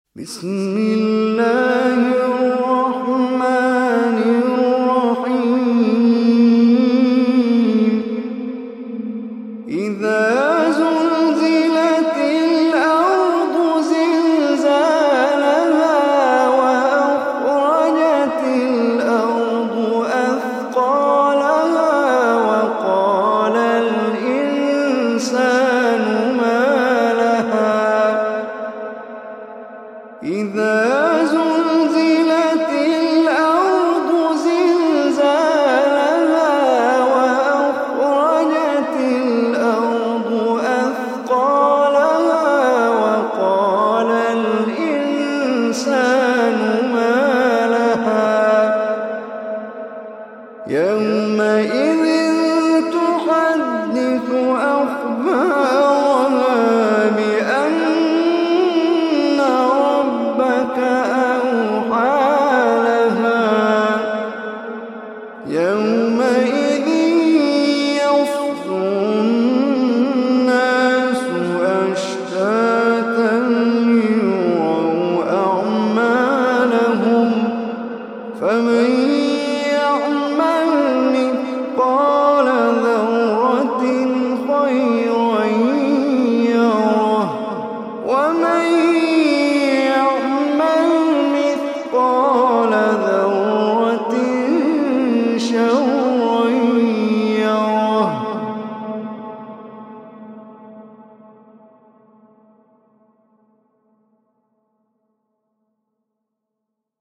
Surah az-Zalzalah Recitation Online Omar Hisham
Surah az-Zalzalah is 99 surah of Holy Quran. Listen or play online mp3 tilawat / recitation in Arabic in the voice of Sheikh Omar Hisham Al Arabi.